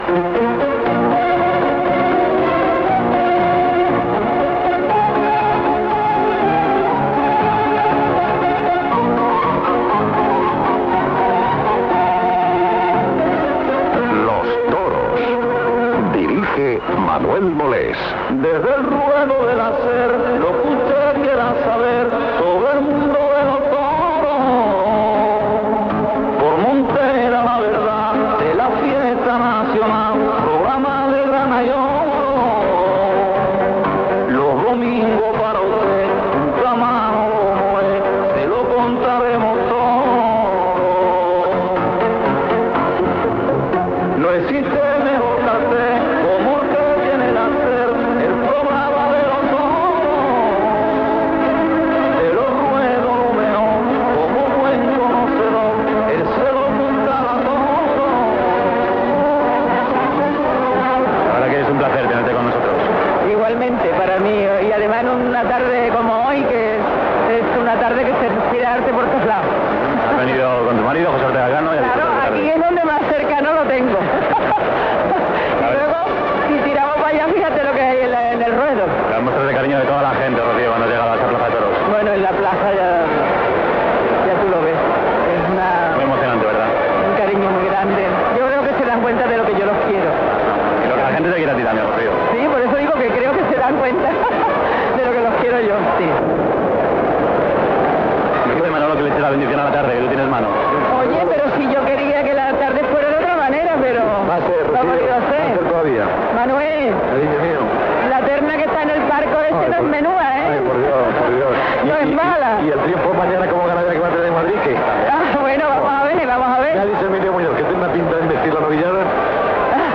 Mort de Rocío Jurado, reposició d'una entrevista a Rocío Jurado, paraules de El Serranito i Antonio Chenel "Antoñete", indicatiu del programa, publicitat, crònica de la correguda de braus de Nimes amb entrevista a Enrique Ponce. Gènere radiofònic Informatiu Anunciant Las ventas Presentador/a Molés, Manolo Data emissió 2006-06-05 Banda OM Localitat Barcelona Durada enregistrament 15:21 Idioma Castellà Any 2006